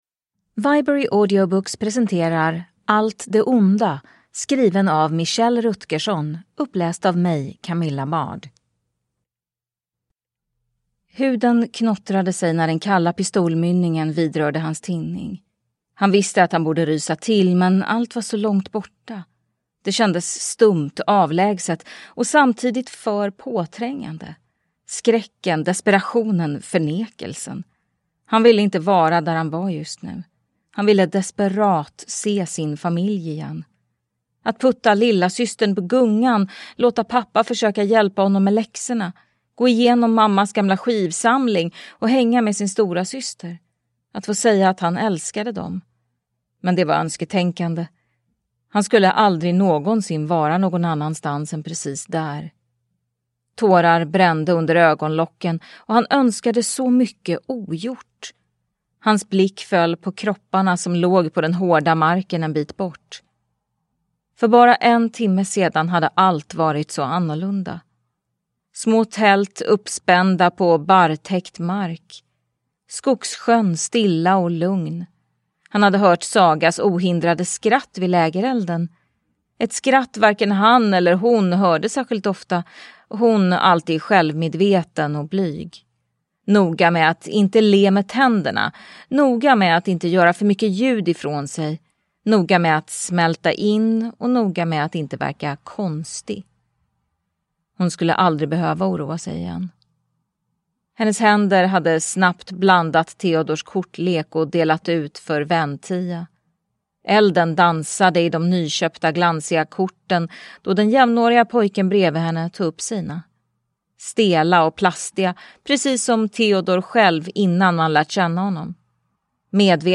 Deckare & spänning
Ljudbok